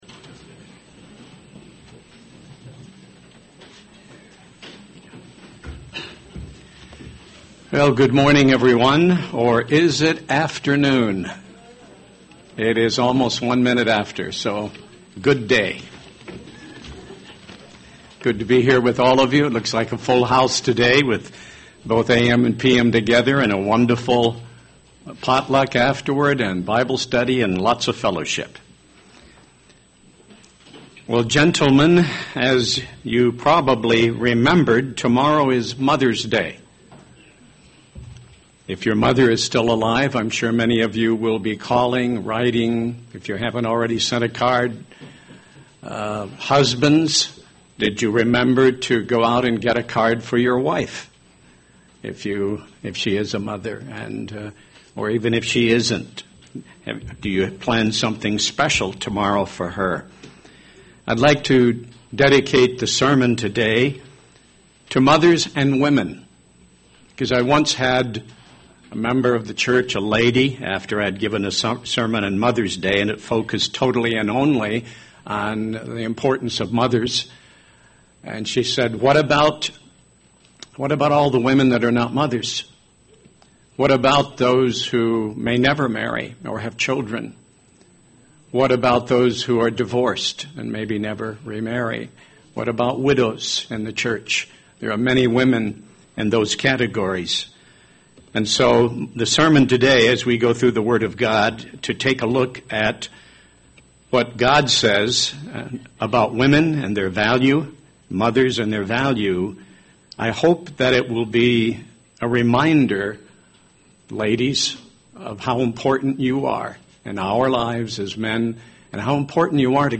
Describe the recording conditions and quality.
Given in Ft. Lauderdale, FL